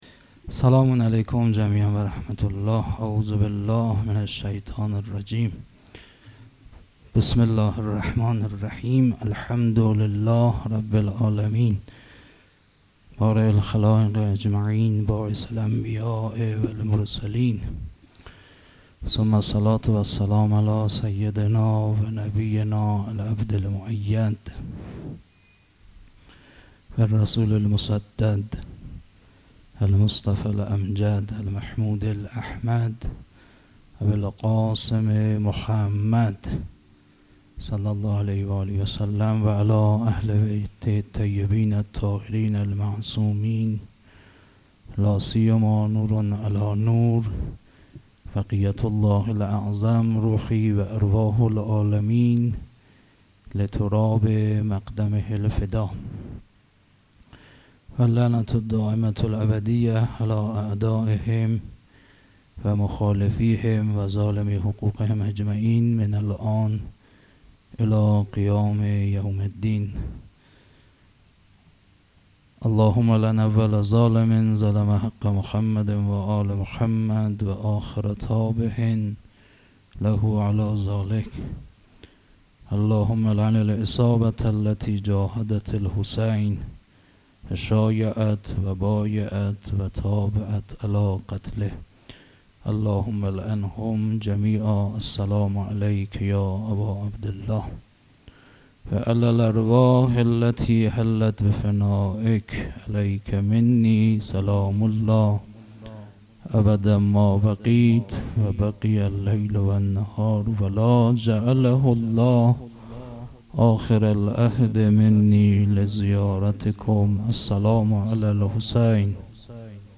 0 0 سخنران